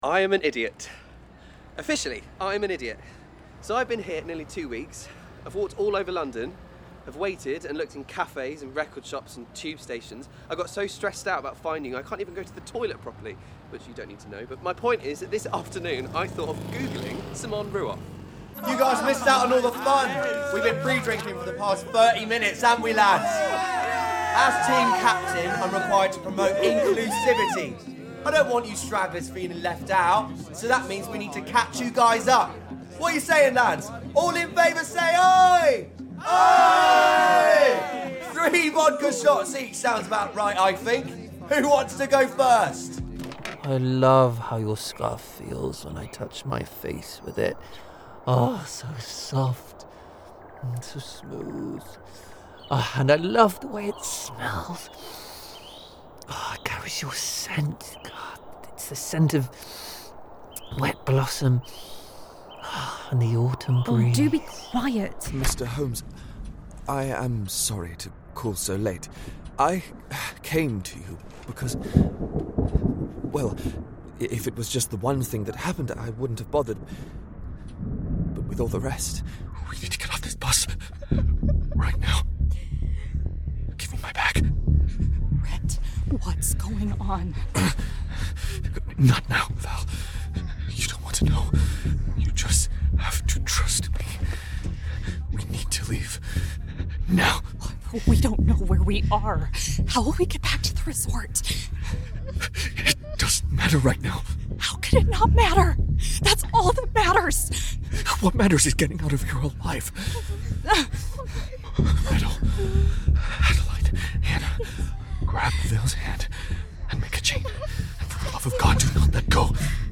Drama Showreel
His approachable, youthful British RP voice has roots in Yorkshire, East Midlands, and Essex.
Male
Neutral British
Confident